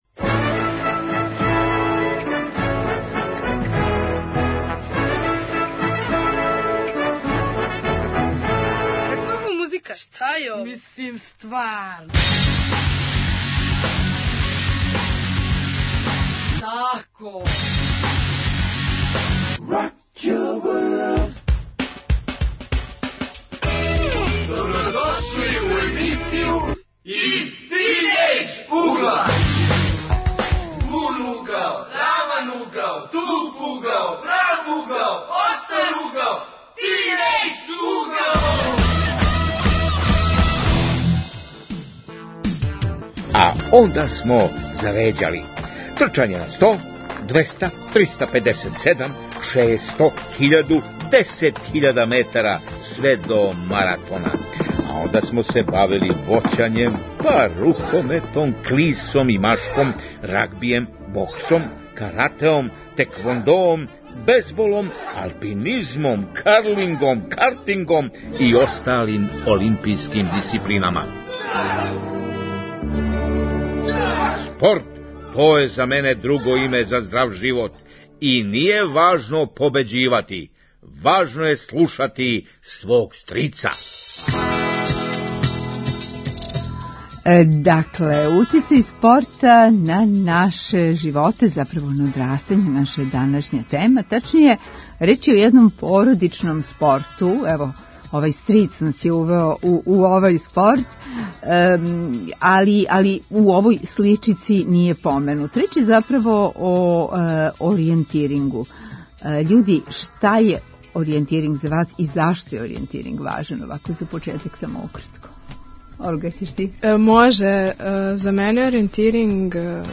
Гости су млади